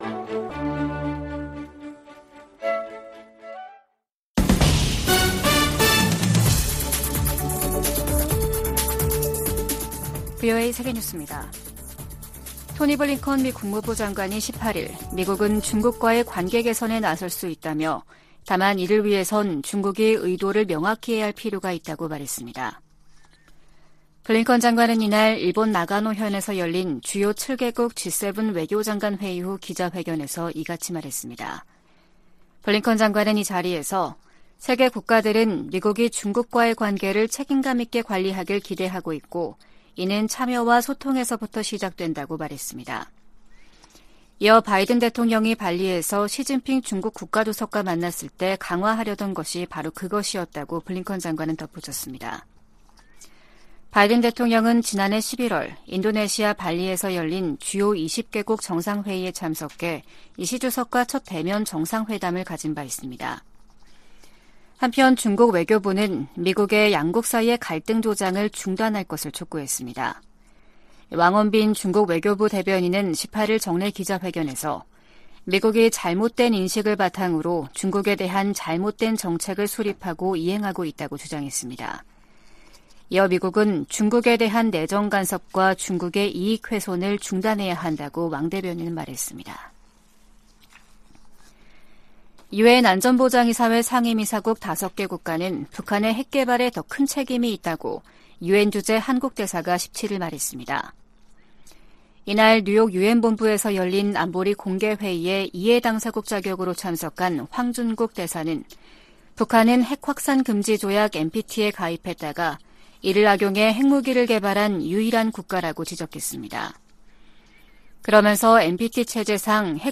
VOA 한국어 아침 뉴스 프로그램 '워싱턴 뉴스 광장' 2023년 4월 19일 방송입니다. 백악관은 정보당국의 도·감청 문건과 관련해 신속한 조치를 취하고 있으며, 파트너 국가들과의 신뢰는 훼손되지 않았다고 평가했습니다. 미 국방부는 도·감청 문제와 관련, 특히 한국과는 매우 좋은 관계를 유지하고 있다고 강조했습니다. 유엔 안전보장이사회가 북한의 신형 대륙간탄도미사일(ICBM) 관련 공개회의를 열고 반복 도발을 강하게 규탄했습니다.